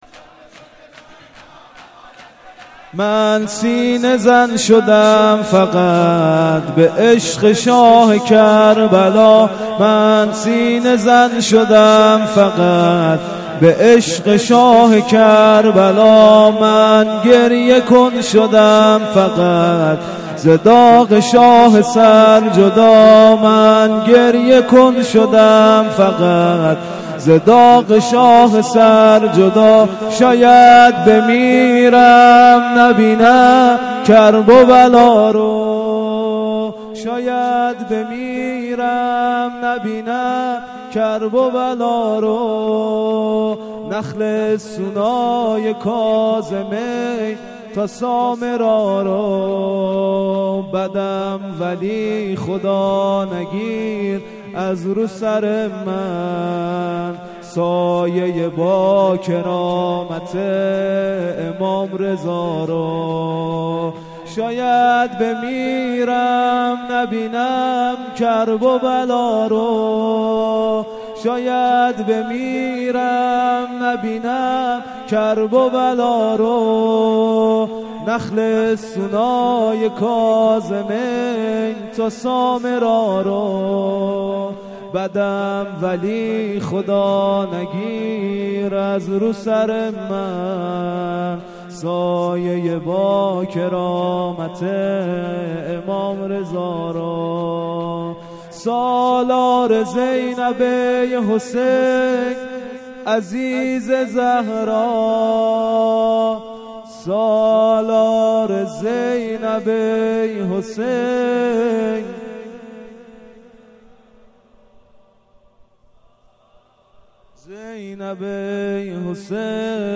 مراسم شب یازدهم دهه اول محرم الحرام ۱۴۳۷ ه.ق هیٔت محبان روح الله (ره)-1394/8/2
شور